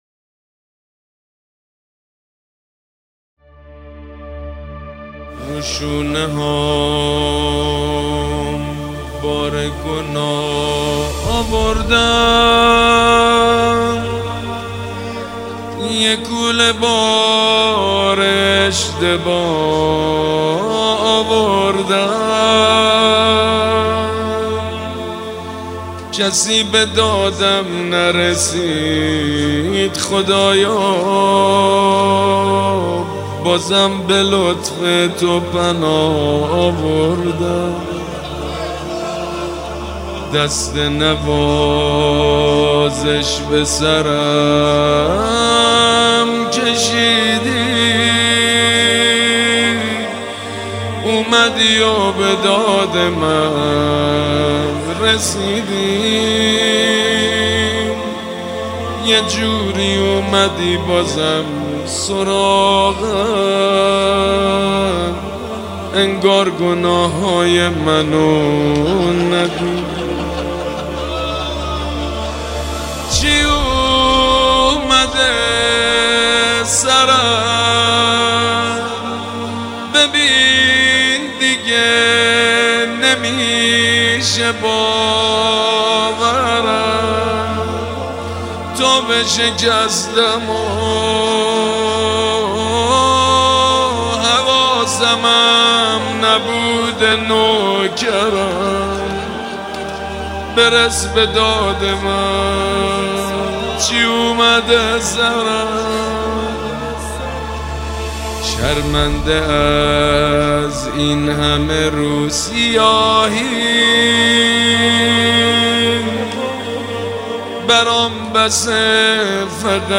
مناجات شب قدر